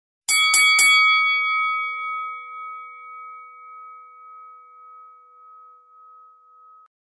Рингтоны » 3d звуки » Боксерский гонг